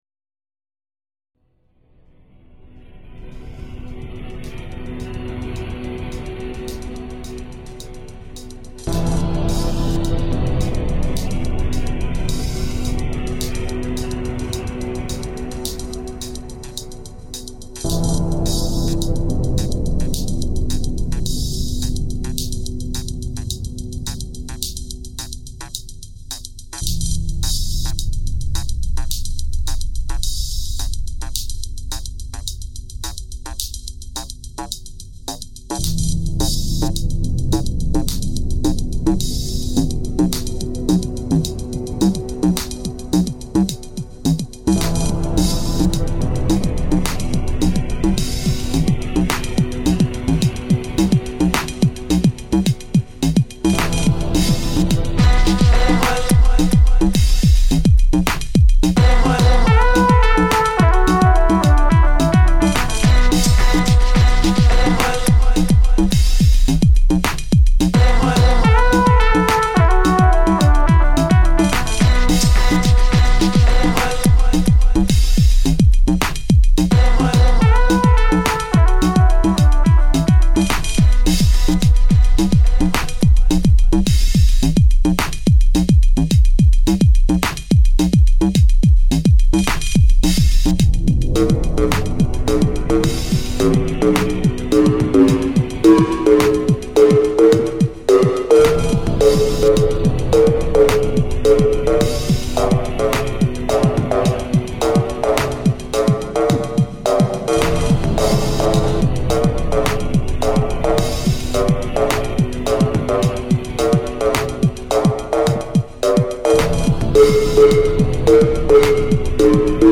Tropical Stomp-Wave for dancing rave-robots of model 99GF, with glitched out Autopilot and sentient rhythm analyzer.